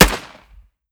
45 ACP 1911 Pistol - Gunshot B 003.wav